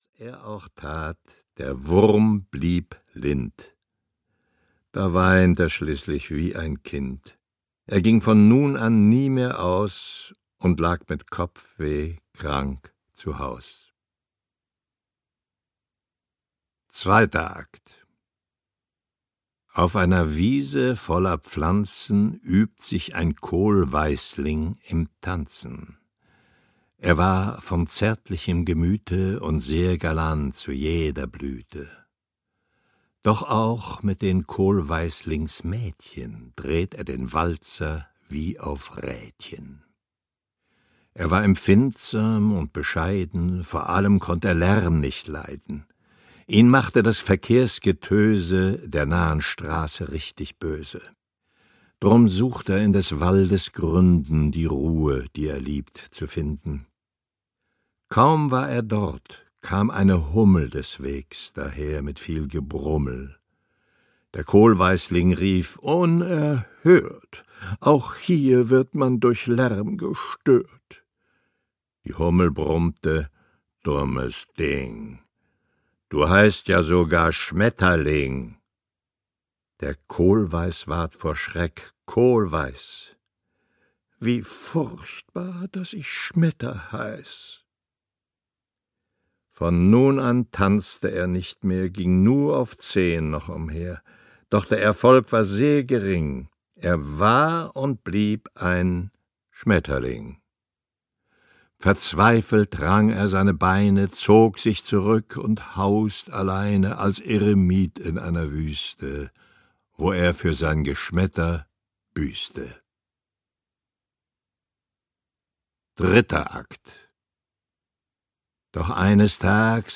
Das große Michael-Ende-Hörbuch - Michael Ende - Hörbuch